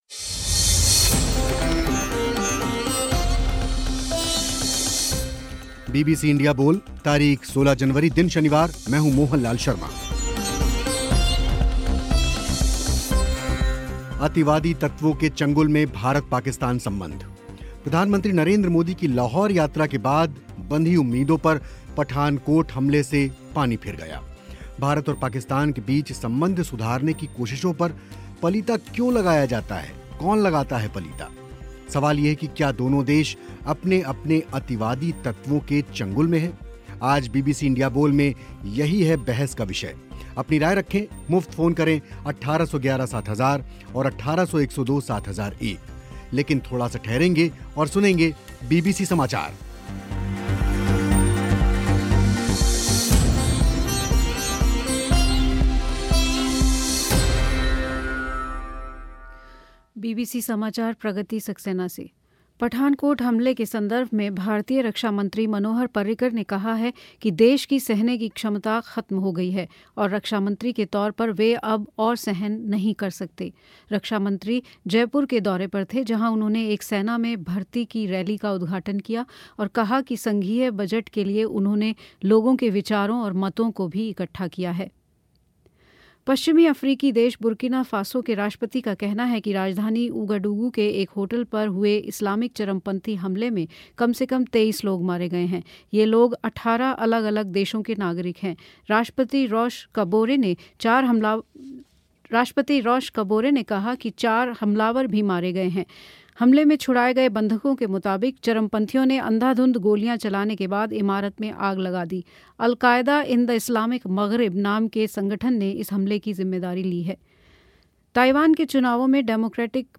बहस कार्यक्रम